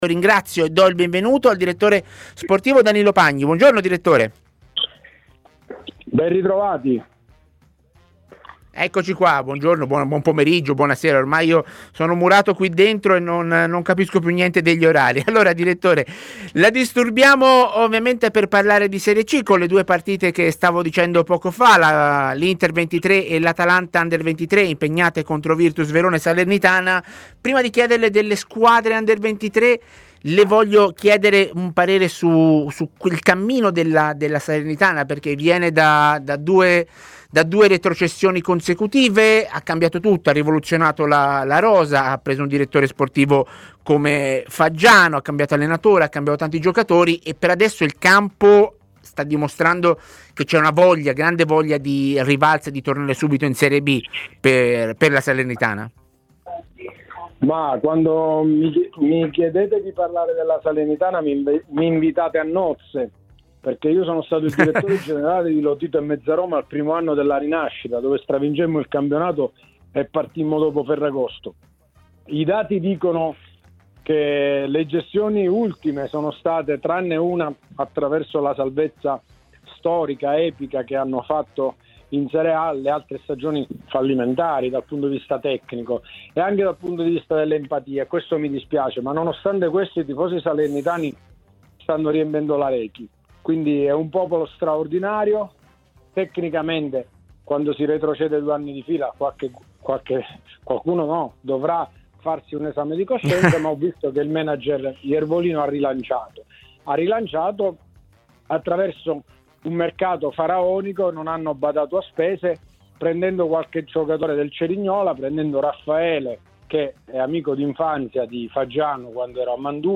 A Tutta C', trasmissione in onda su TMW Radio e Il 61 , per parlare dei temi di attualità legati al campionato di Serie C.